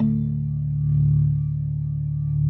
B3LESLIE D 2.wav